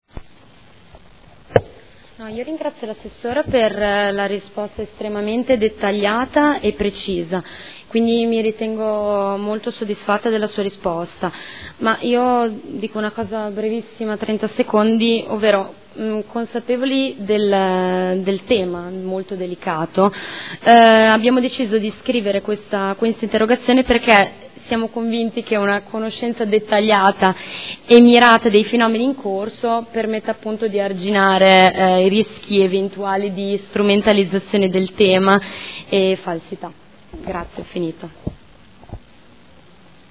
Federica Venturelli — Sito Audio Consiglio Comunale